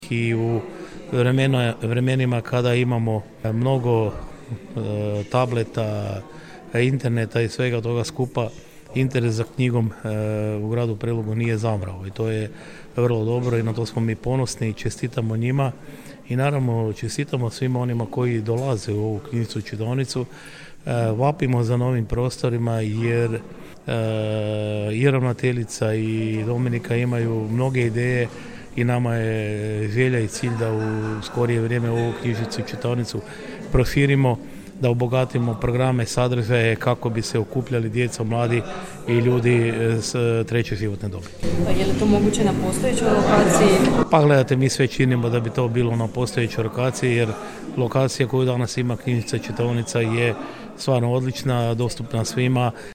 Novo proširenje planira se na postojećoj lokaciji, a podršku će i ovog puta dati Grad Prelog, obećao je gradonačelnik Ljubomir Kolarek: